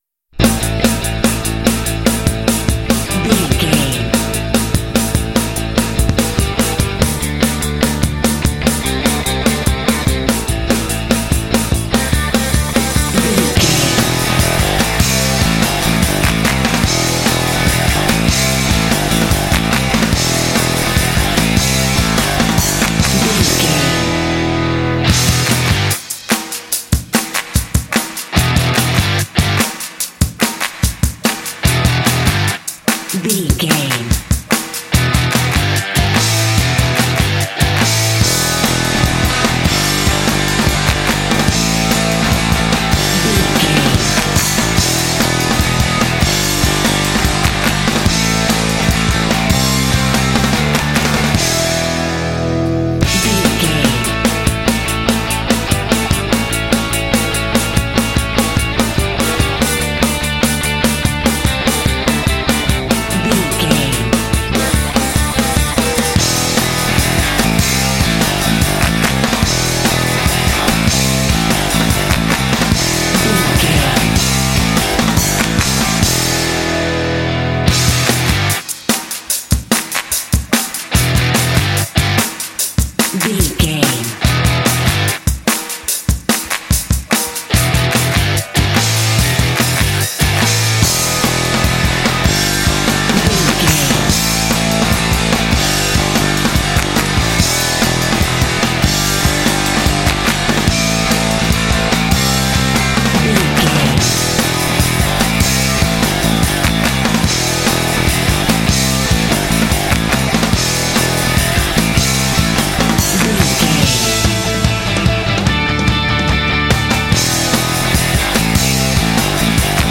Aeolian/Minor
energetic
powerful
aggressive
drums
bass guitar
electric guitar
classic rock
heavy metal